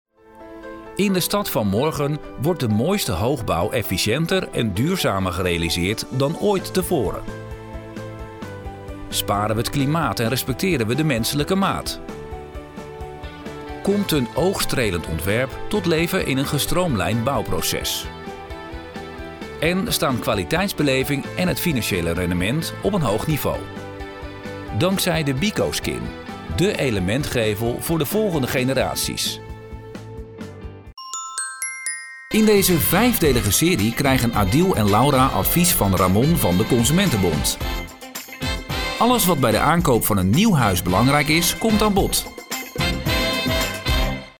Natuurlijk, Opvallend, Toegankelijk, Vertrouwd, Vriendelijk
Corporate